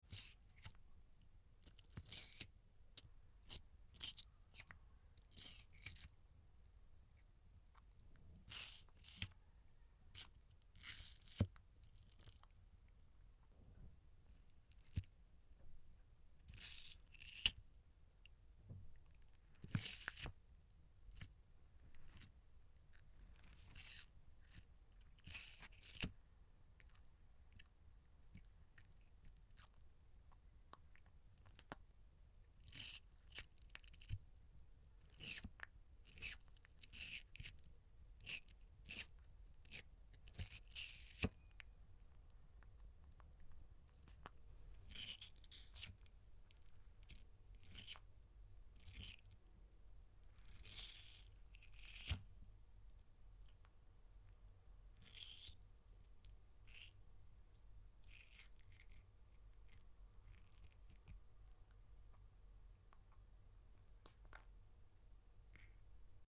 The sound of a turtle sound effects free download
The sound of a turtle eating an apple